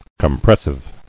[com·pres·sive]